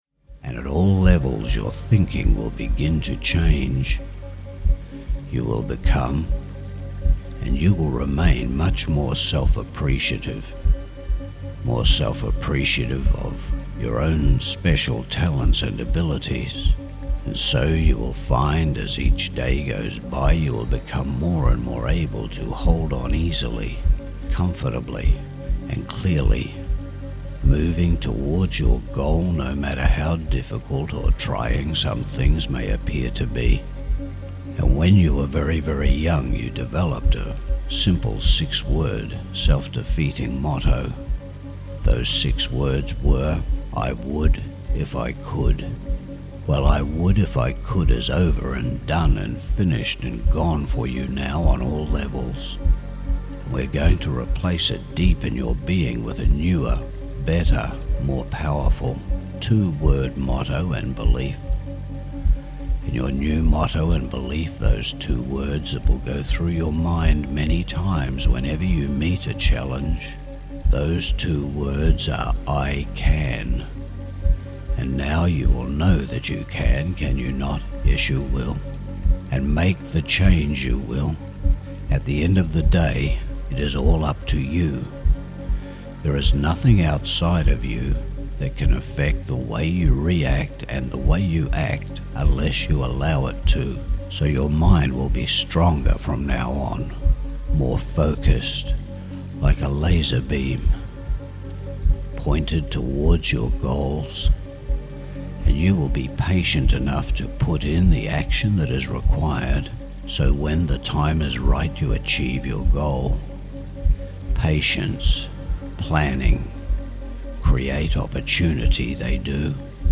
Beat Shopping Addiction Hypnosis MP3